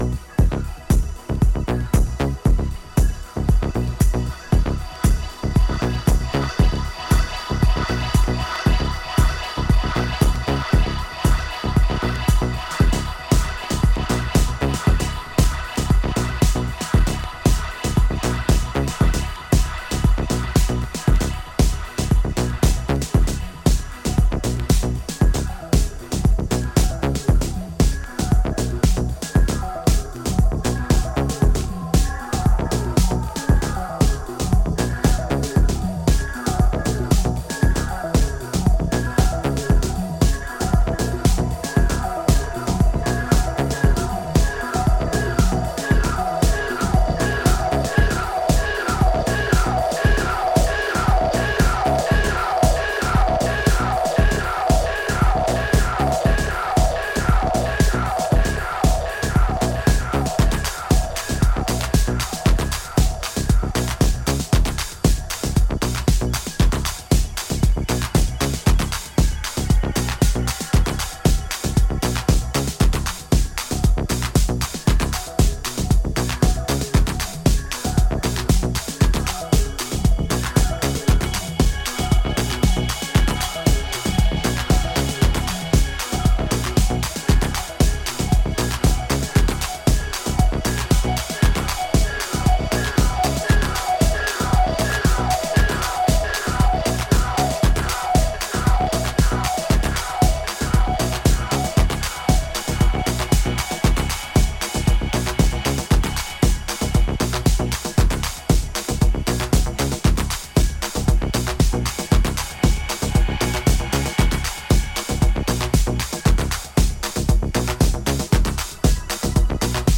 クリアなトーンのシンセレイヤーが印象的なテンポ抑えめの13分に及ぶアシッド・ハウス